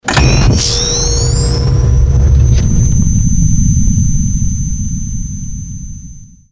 Звук включения устройства для втягивания призраков